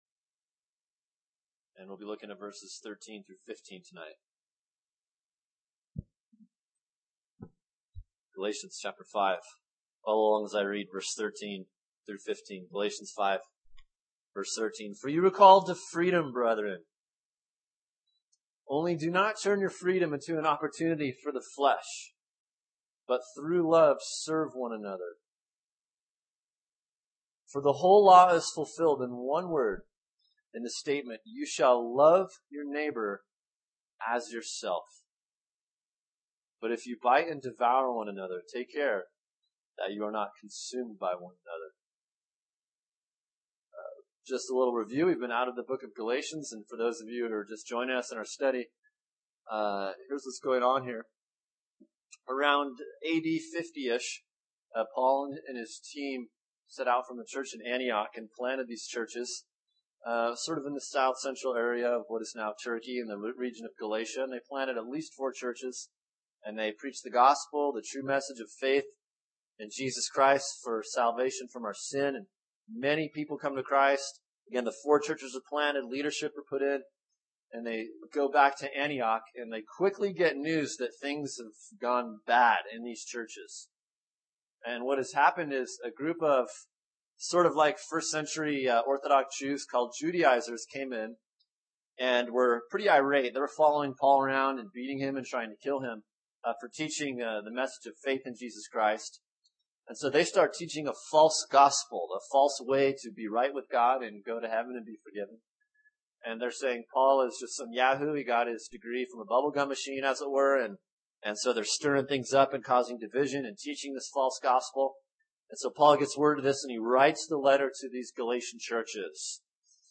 Sermon: Galatians 5:13-15 “The Power of Freedom” | Cornerstone Church - Jackson Hole